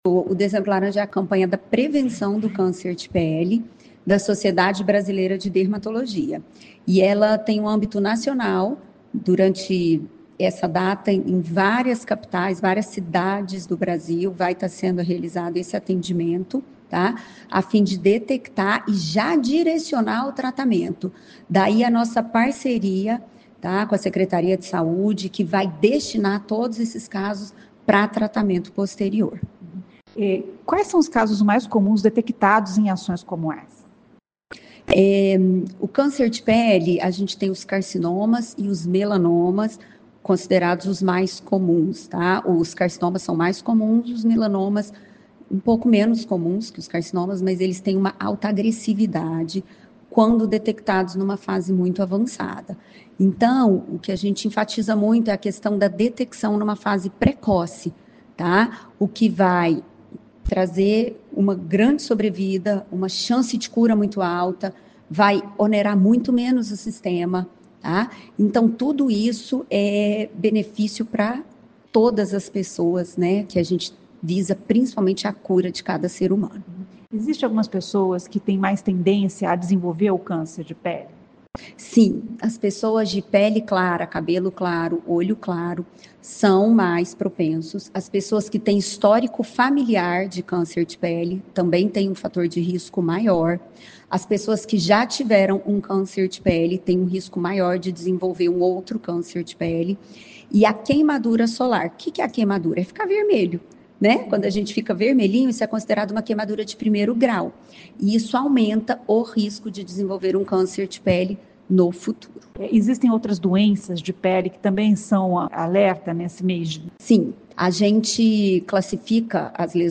A dermatologista